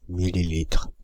Ääntäminen
Synonyymit centimètre cube cc Ääntäminen France (Île-de-France): IPA: /mi.li.litʁ/ Haettu sana löytyi näillä lähdekielillä: ranska Käännöksiä ei löytynyt valitulle kohdekielelle.